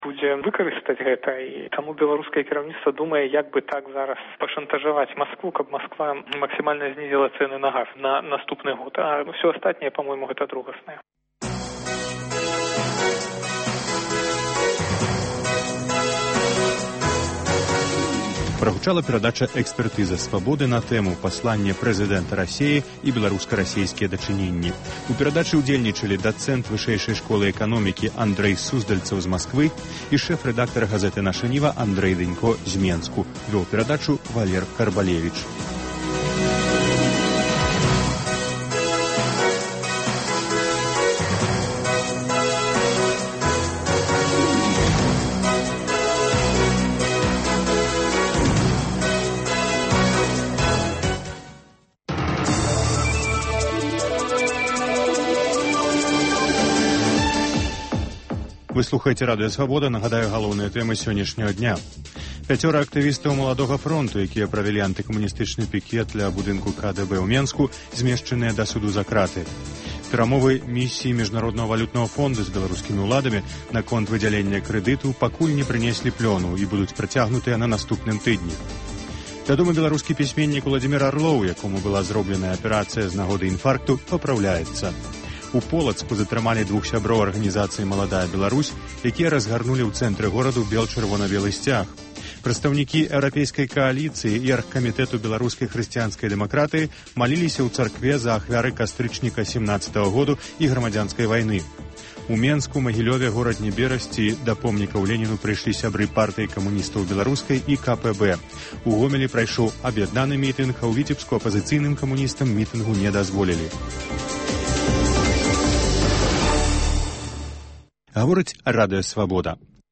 гутарыць з гісторыкам Алегам Трусавым